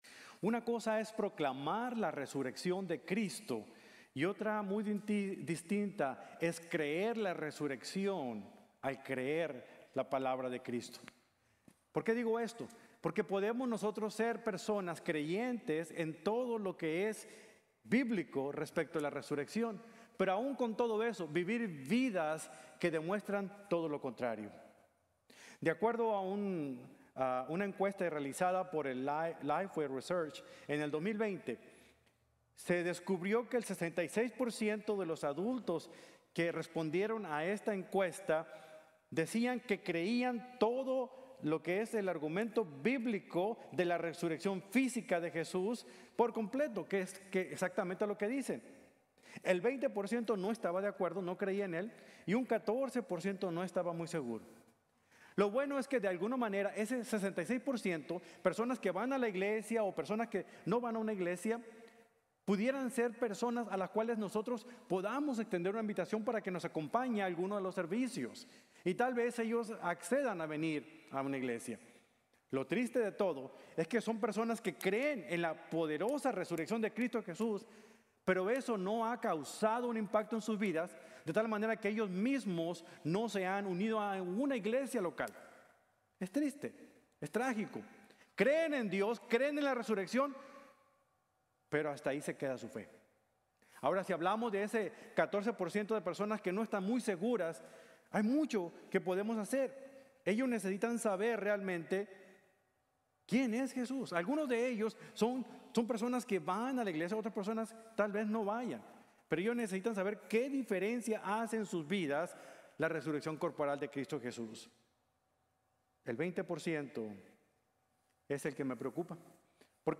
Una Mañana Más Allá de las Creencias | Sermon | Grace Bible Church